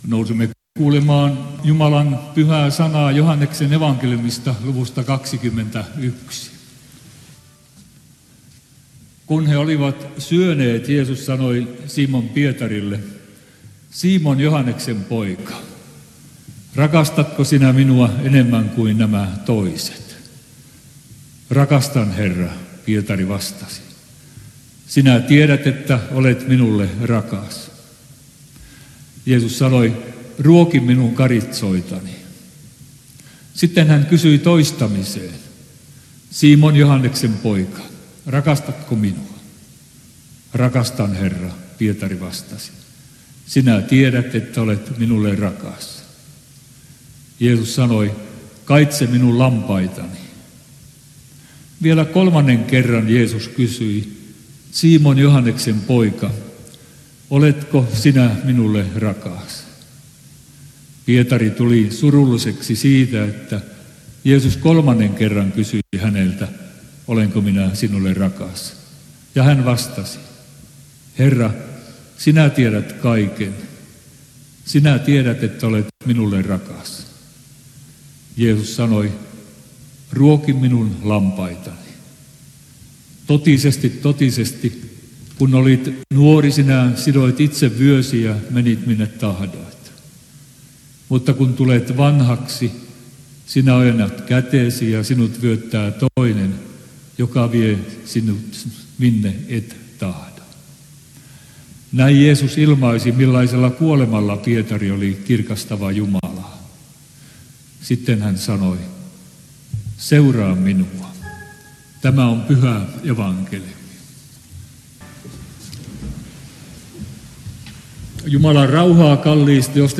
Toholampi